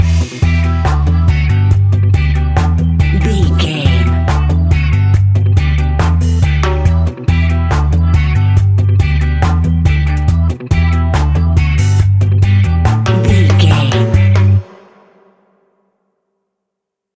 Classic reggae music with that skank bounce reggae feeling.
Aeolian/Minor
instrumentals
laid back
off beat
drums
skank guitar
hammond organ
percussion
horns